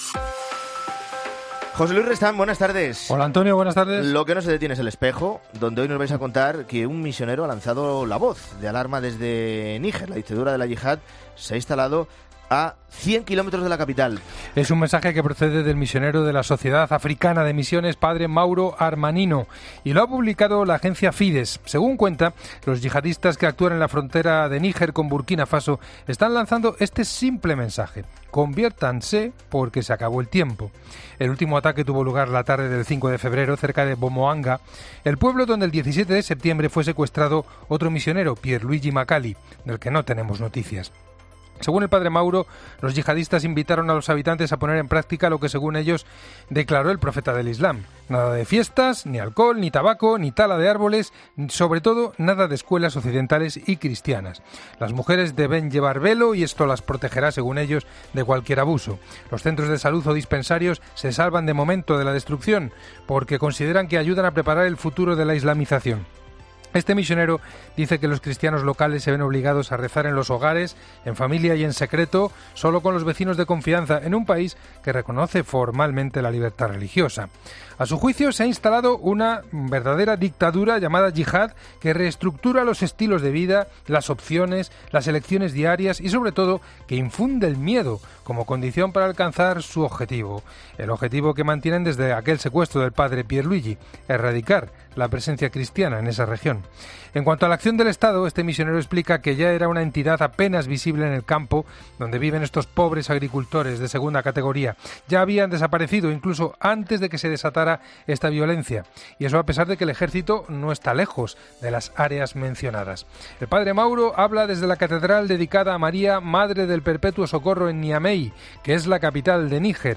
AUDIO: Hoy historia del día, entrevista y actualidad eclesial.